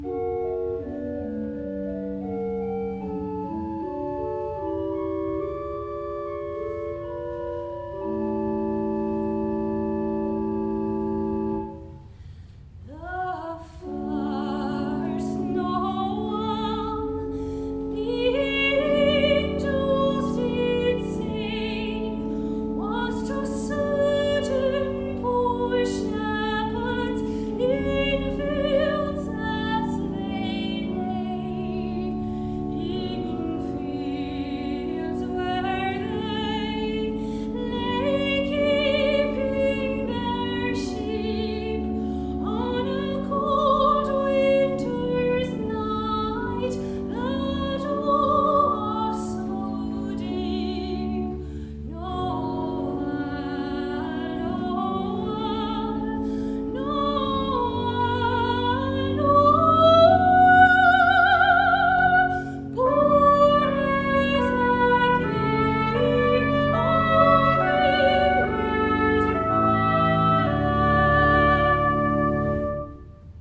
concert de Noël – église Ste Croix de Lorry
–  Voix et Orgue